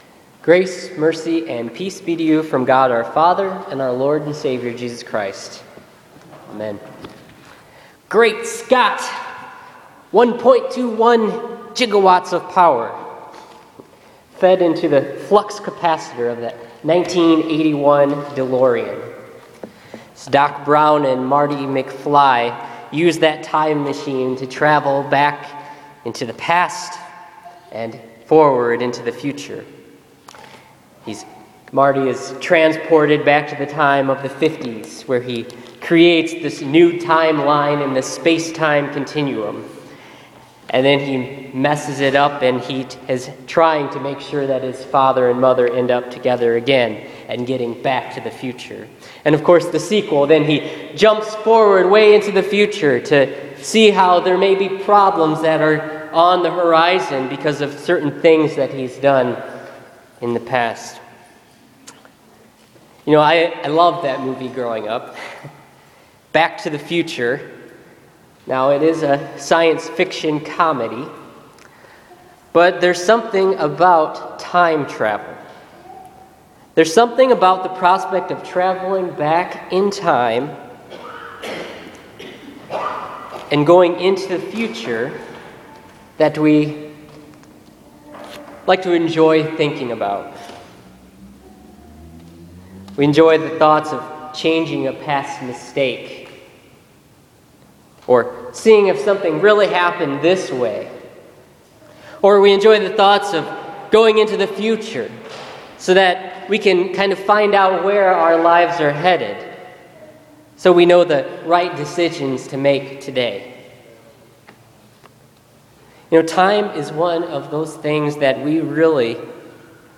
Listen to this week’s sermon for the 12th Sunday after Pentecost.